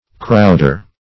Crowder \Crowd"er\ (kroud"[~e]r), n.